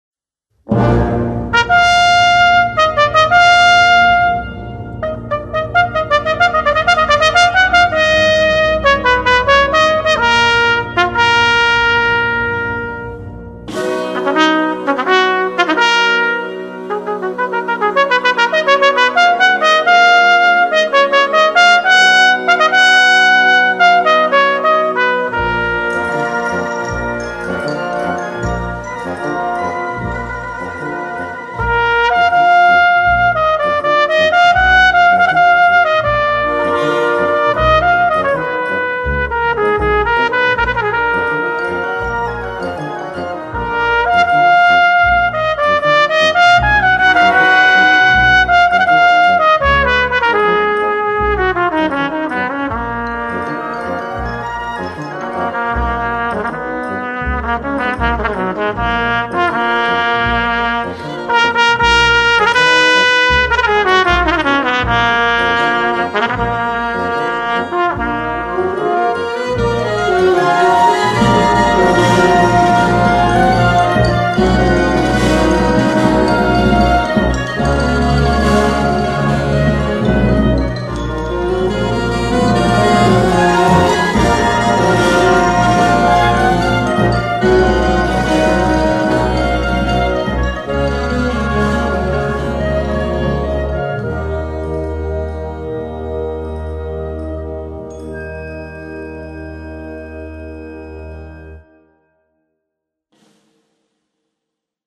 Voicing: Trombone and Brass Band